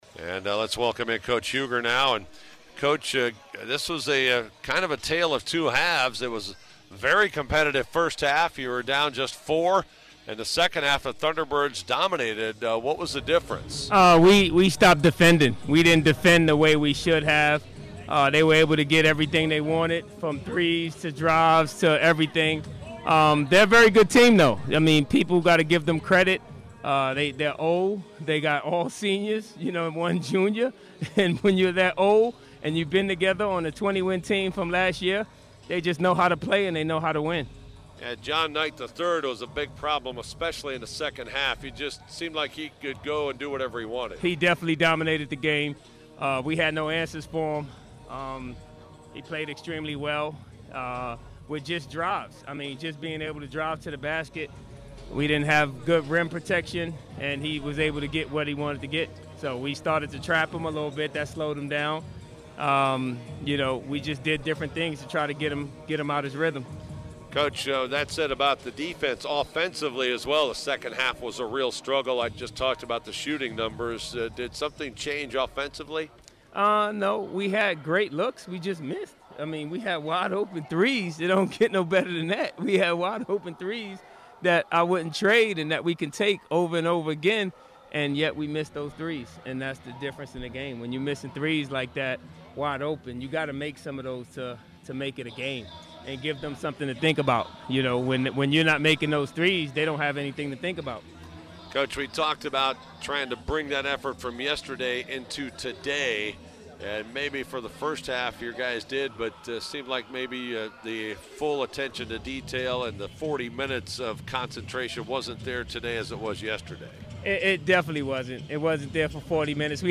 Full Postgame Interview